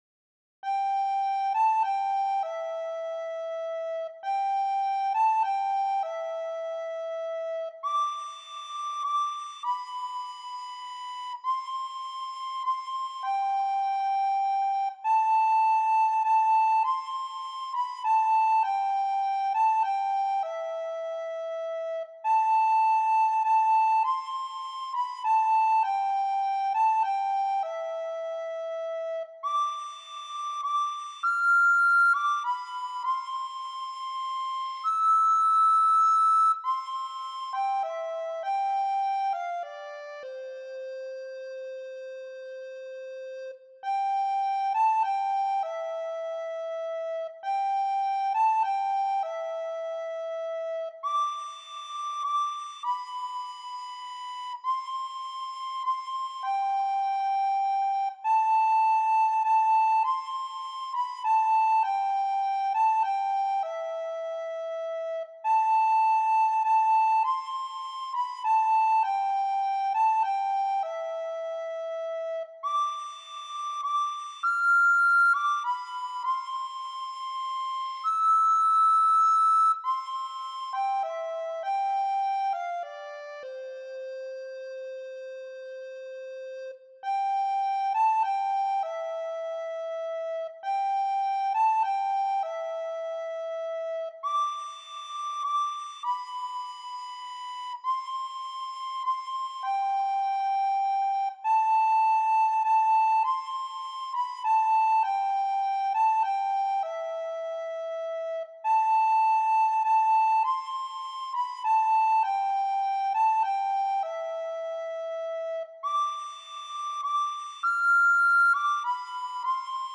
für Sopranblockflöte solo